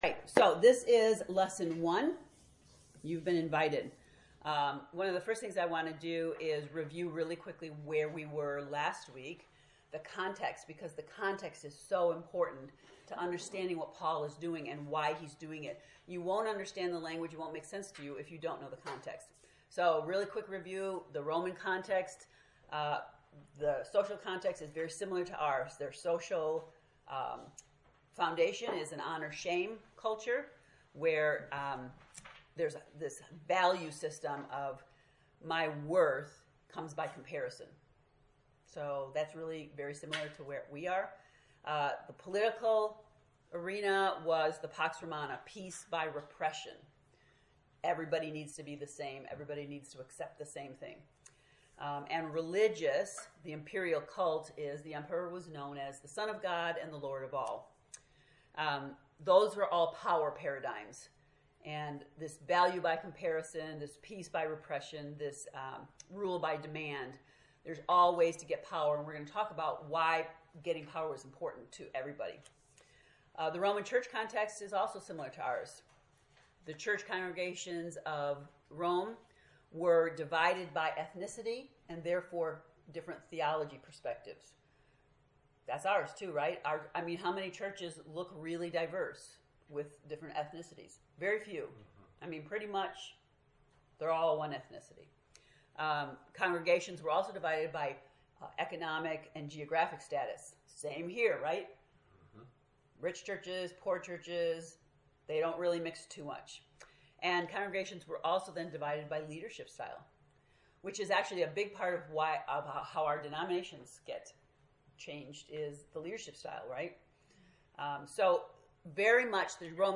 To listen to the lesson 1 lecture, “You’ve Been Invited,” click below: